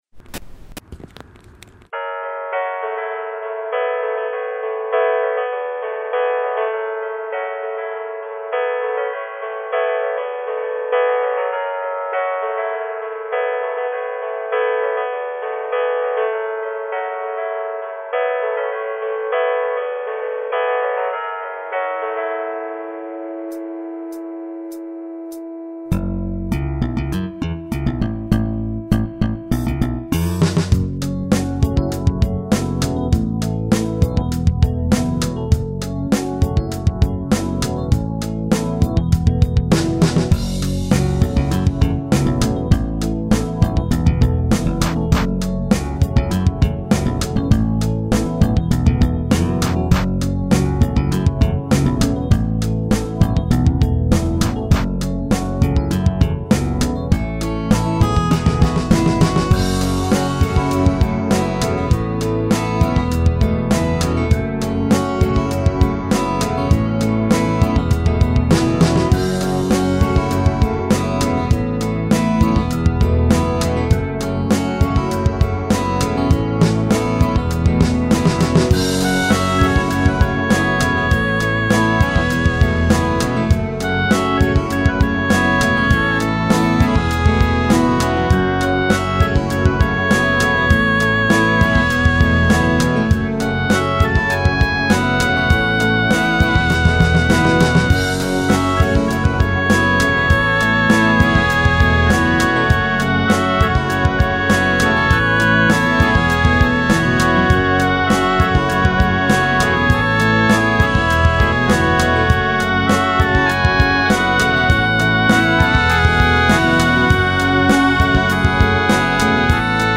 Theme: freestyle warmup
The sound wasn't at all what I originally intended; I had envisioned it as an upbeat electronic dance track, but instead it settled into a laid-back groove which I think suits it much better. Despite its length, this isn't what I would call an actual song-- there are no significant musical changes, just layers over a constant theme.
I'm really happy with the loudness.
The weak point is the transition out of the break, near the end.
The lead melodies on bass and oboe were improvised on the spot.
I normally pad obsessively, but the digital piano choruses enough that I didn't need to with this one.